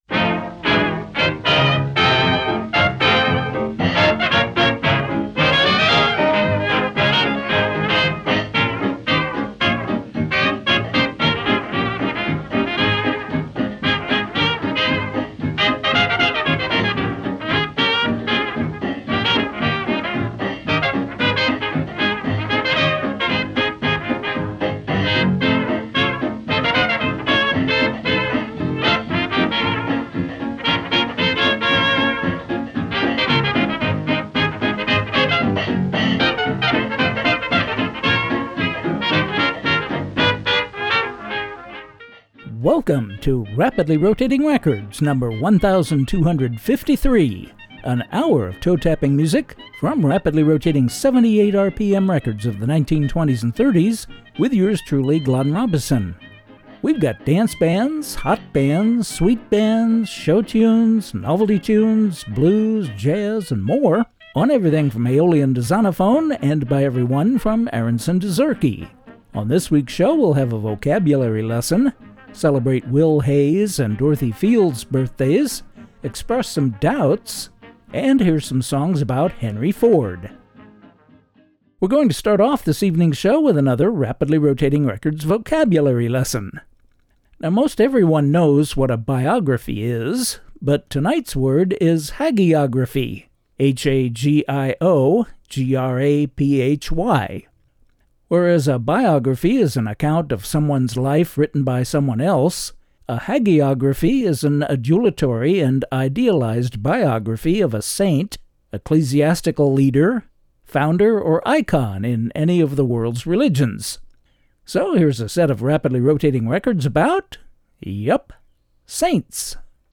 bringing you vintage music to which you can’t not tap your toes, from rapidly rotating 78 RPM records of the 1920s and ’30s.